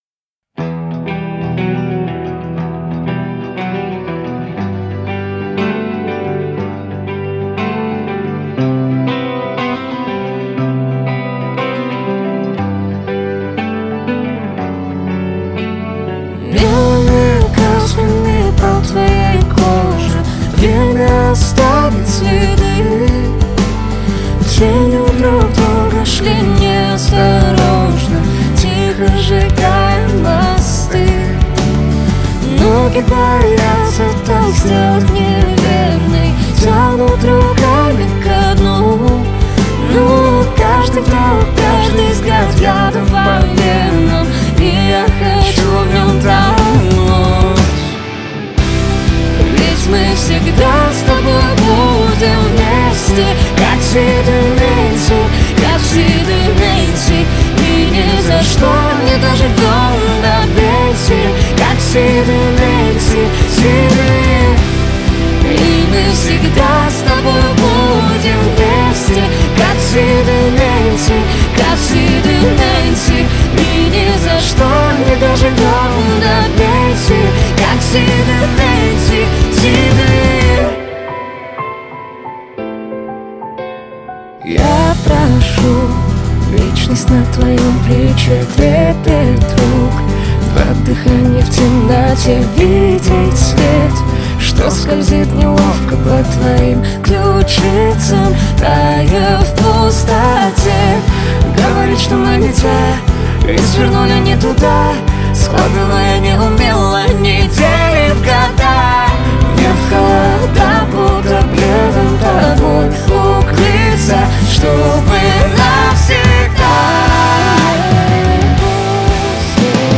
Рейв